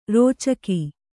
♪ rōcaki